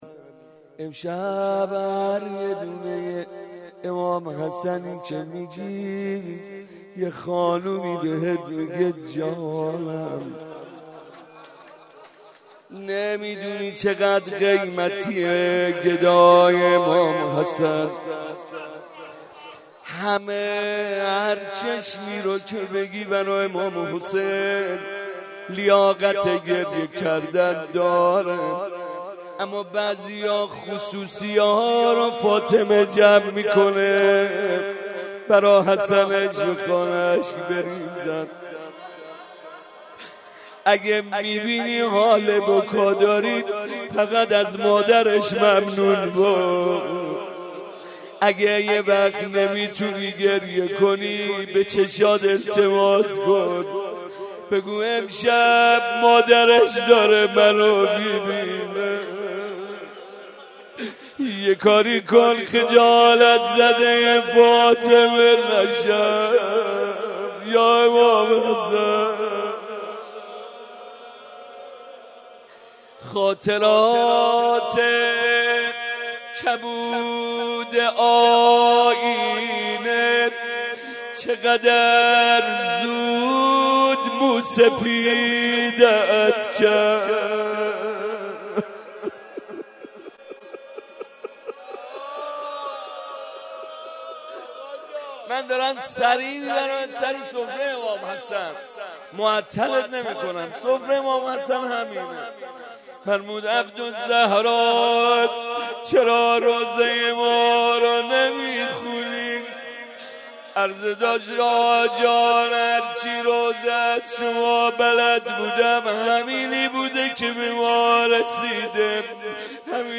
روضه
روضه محمدرضا طاهری مداح اطلاعات عنوان : روضه مناسبت : شب دوم رمضان سال انتشار : 1392 مداح : محمدرضا طاهری قالب : روضه موارد مشابه صوتی کربلا سخت مبتلا می خواست هلال ماه خدا دمیده امشب روضه مناجات باز کن در منم منم یا رب